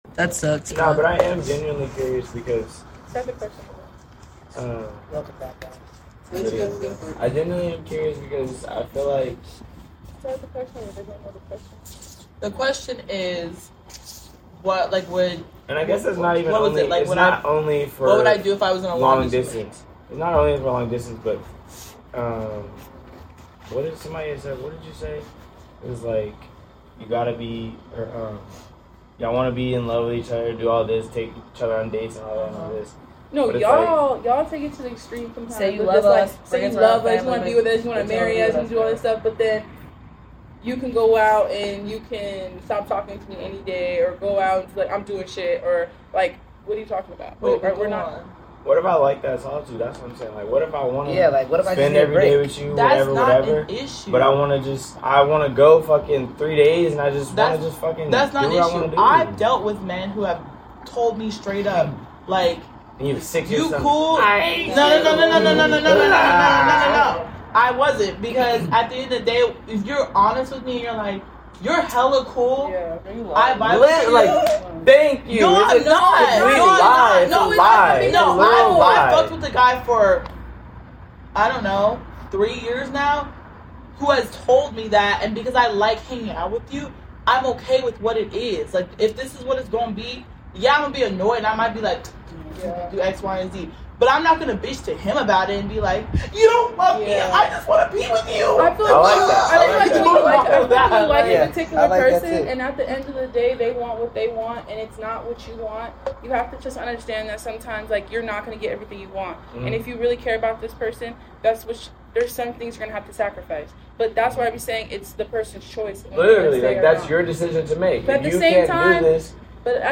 Just two Best Friends in their twenties talking shit!